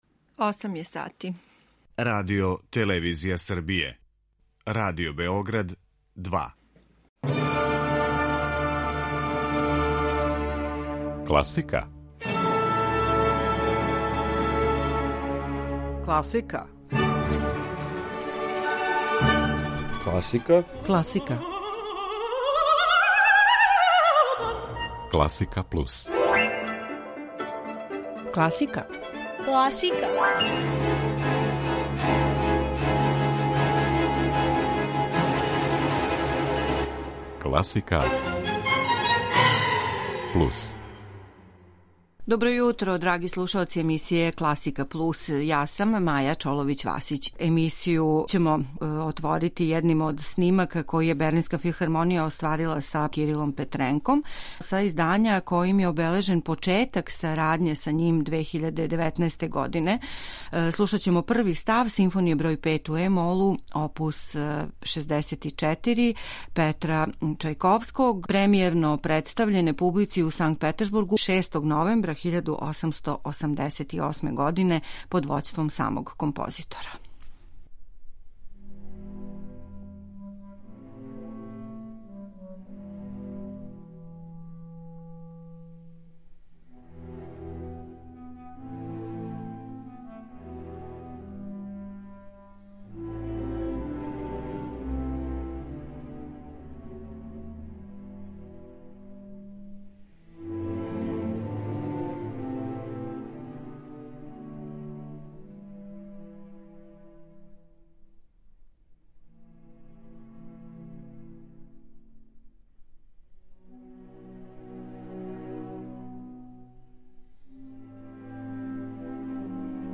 Избор снимака врхунских уметника и ансамбала
пијаниста
клавирски трио